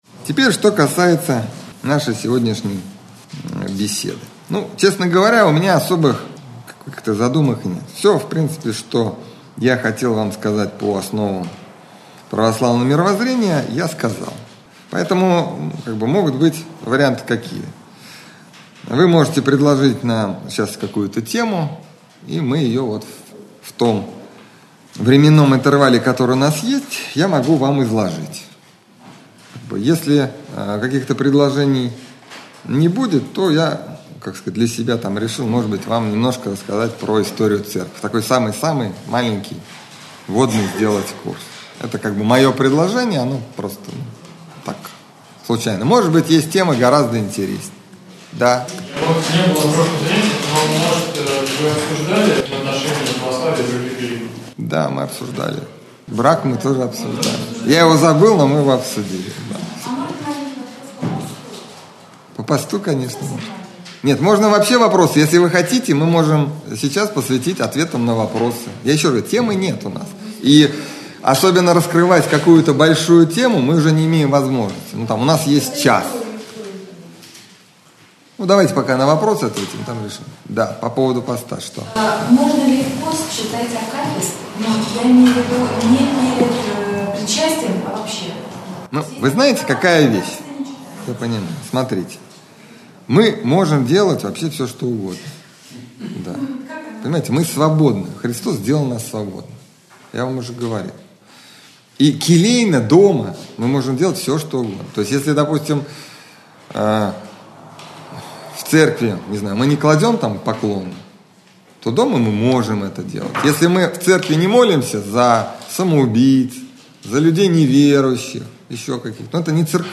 Общедоступный православный лекторий